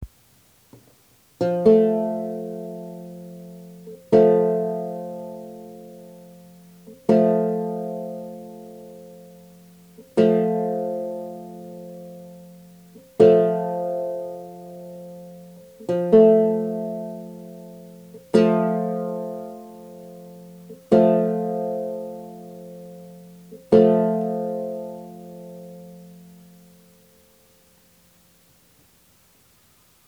Para ilustrar las diferencias he afinado la tercera mayor Fa – La de mi vihuela en diferentes temperamentos:
• 3ª mayor en Mesotónico (698)
Al margen de que tengo las cuerdas del instrumento un tanto envejecidas y cuesta tener ambas perfectamente al unísono, se pueden escuchar diferencias muy palpables.
La del temperamento Mesotónico atenuado (el que estoy usando fue propuesto por Eugen Dombois con quintas de 698) es mucho más estable, pero la máxima estabilidad, como es obvio, se la lleva el 1/4 de coma cuya tercera mayor es acústicamente pura.
3-mayor-en-mesotonico-698.ogg